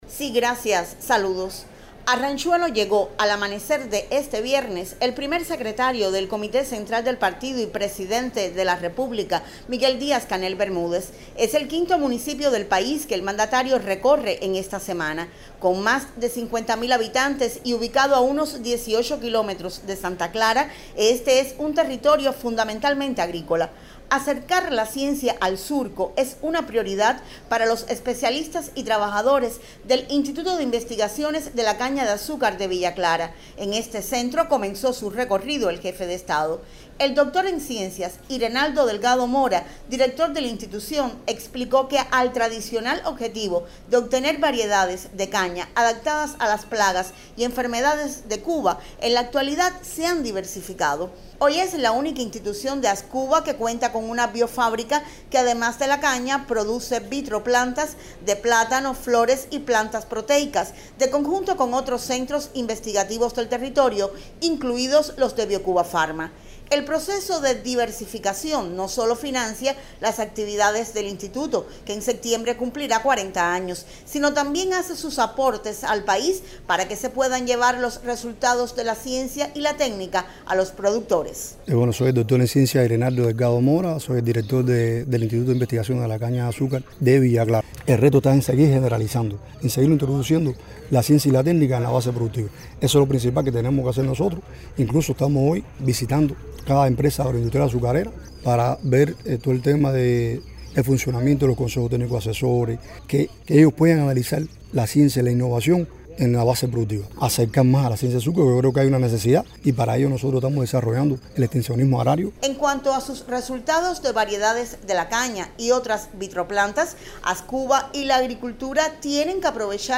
En la mañana de este viernes, el Primer Secretario del Comité Central del Partido y Presidente de la República, continuó por Villa Clara sus sistemáticos encuentros con las bases